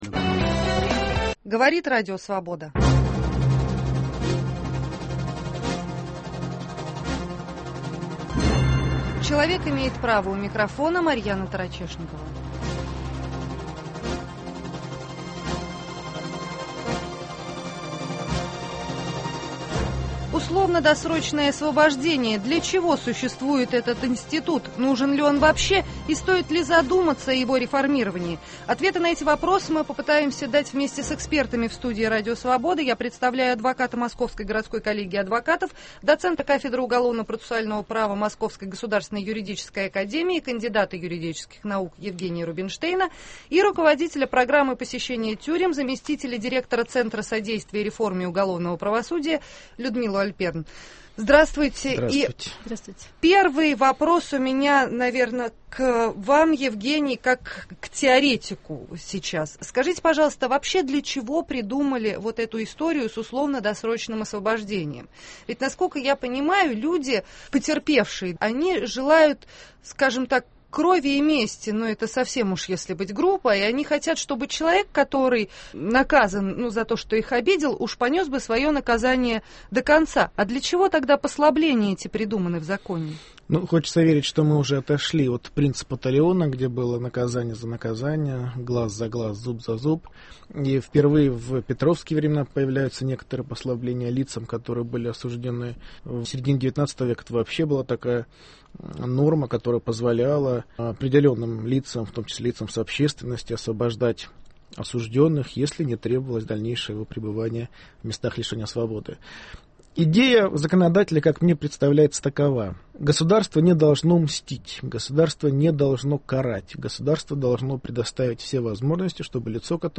Для чего существует условно-досрочное освобождение? Нужна ли вообще эта процедура и стоит ли задуматься о её реформировании? В студии РС